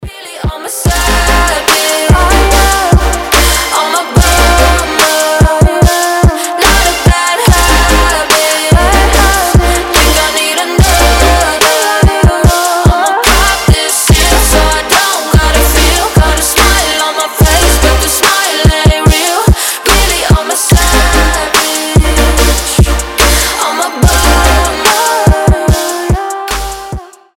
• Качество: 320, Stereo
женский голос
Electronic
басы
future bass